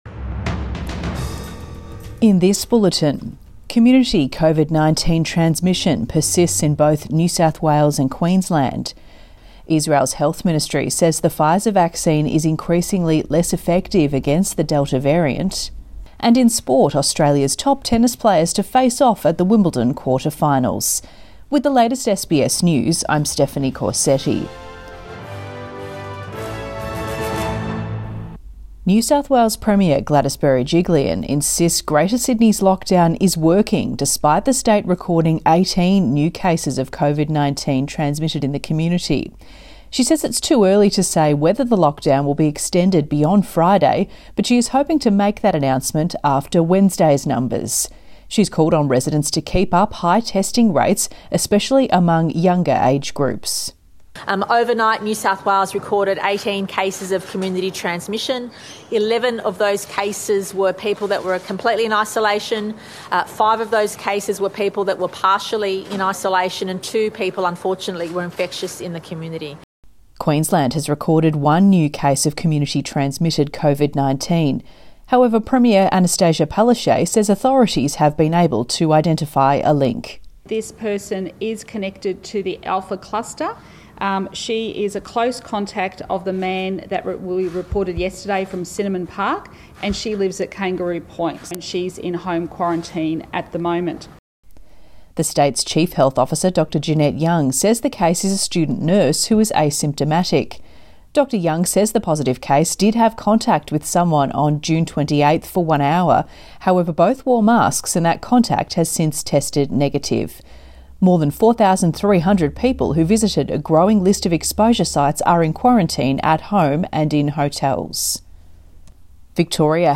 Midday bulletin 06 July 2021